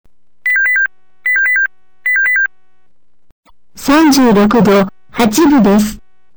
温度上昇がほとんどなくなると、「ピポピポ」とブザーが3回鳴り、検温結果が音声案内されます。